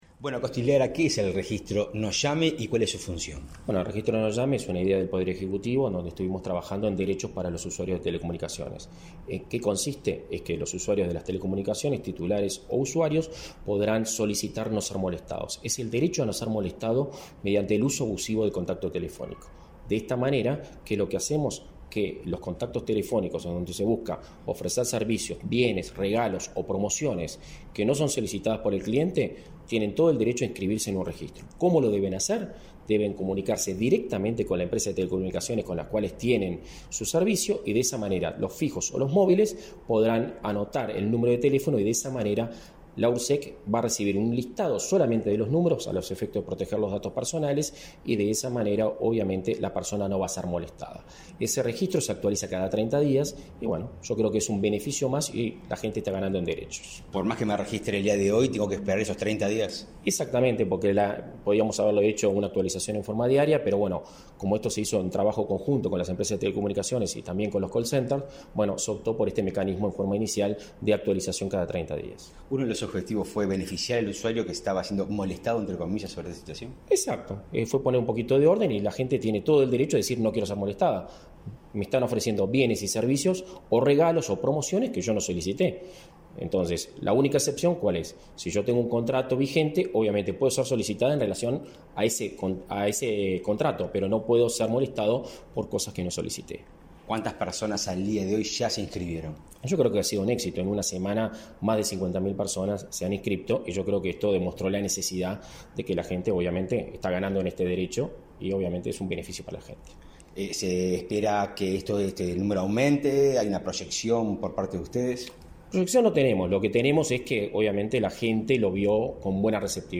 Entrevista al director nacional de Telecomunicaciones y Servicios de Comunicación Audiovisual, Guzmán Acosta y Lara